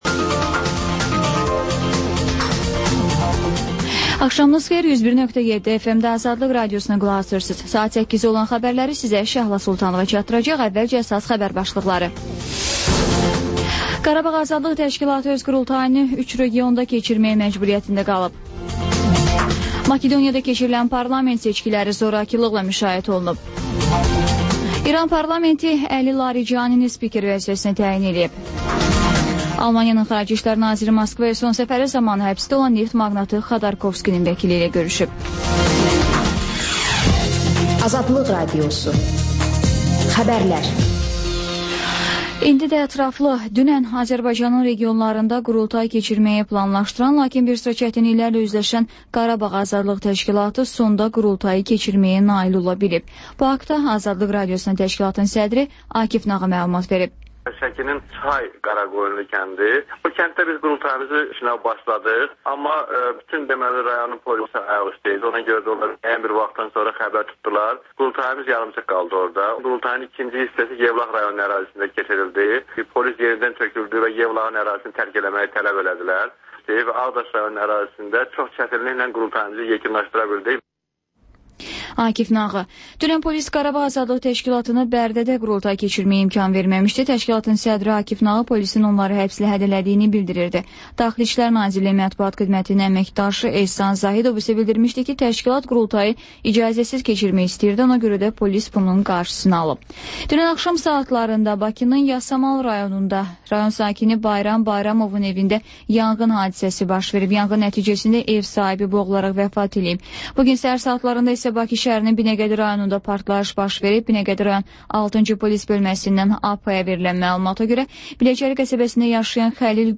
Xəbərlər, İZ: Mədəniyyət proqramı və TANINMIŞLAR rubrikası: Ölkənin tanınmış simaları ilə söhbət